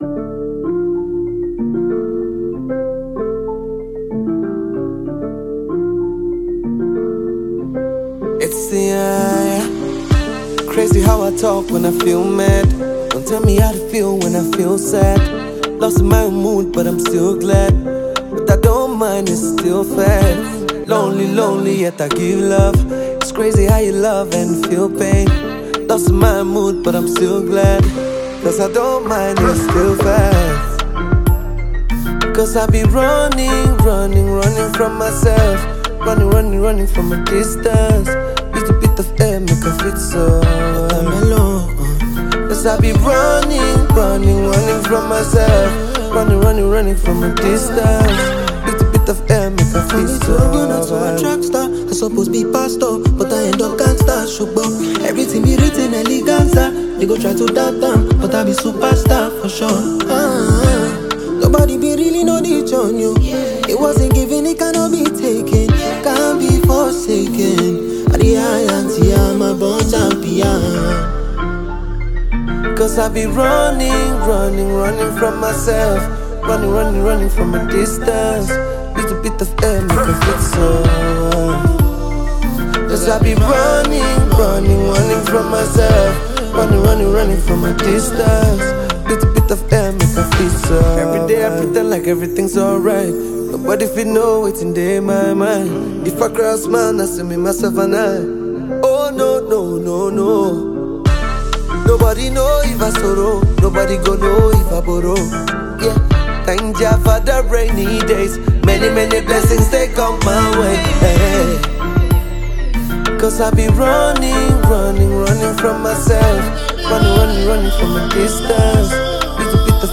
Foreign MusicNaija Music